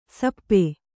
♪ sappe